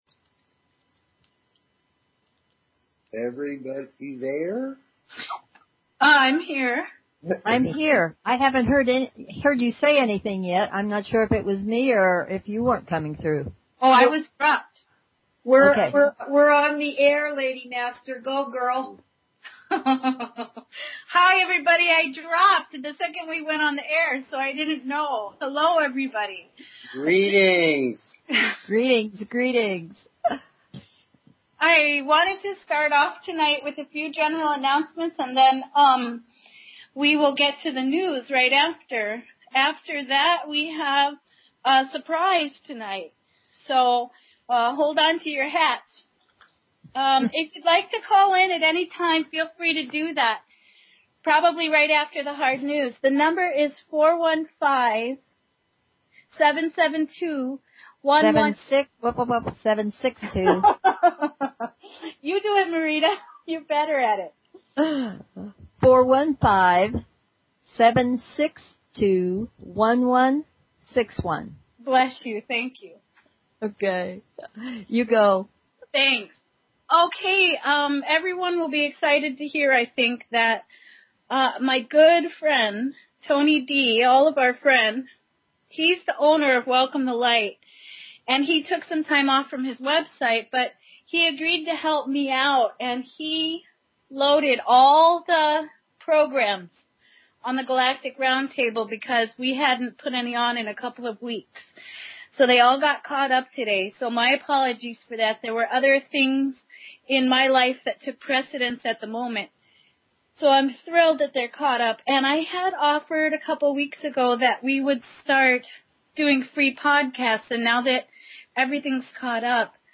Talk Show Episode, Audio Podcast, Galactic_Healing and Courtesy of BBS Radio on , show guests , about , categorized as